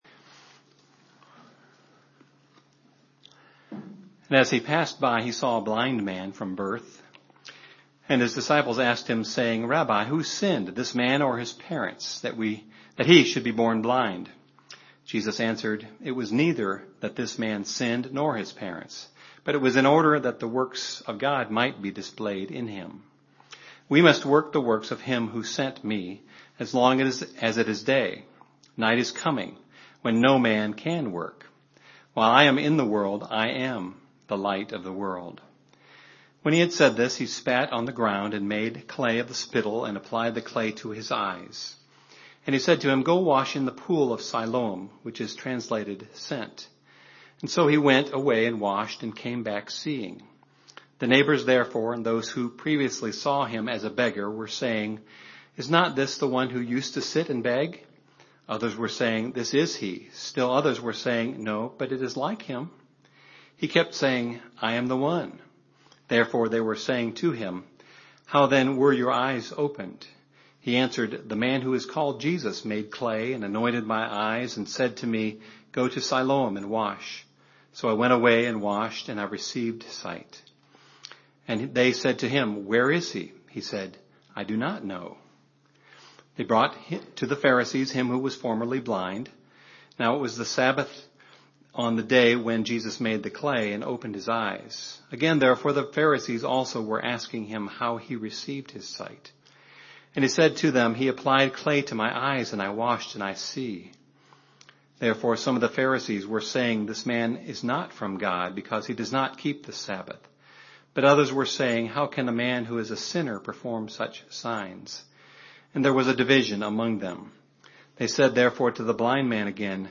Categories: 2014, Lent, Passion Week, Sermons, The Life and Ministry of Jesus